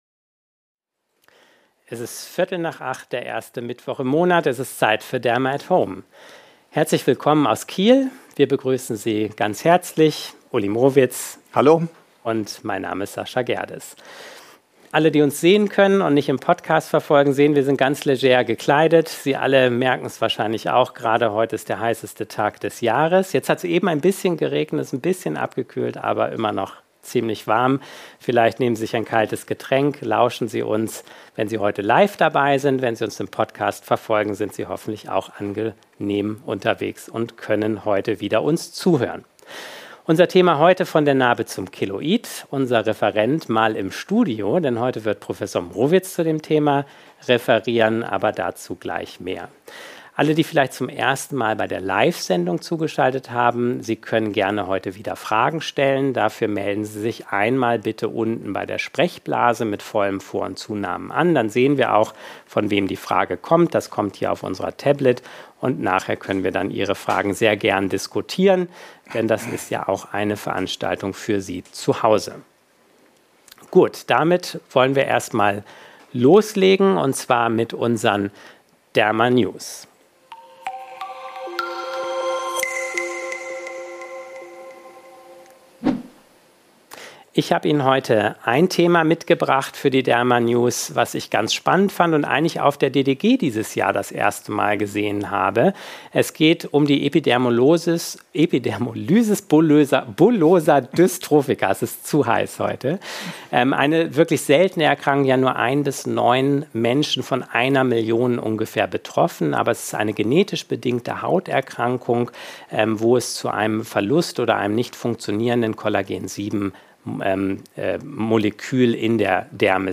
In dieser Episode referiert der Experte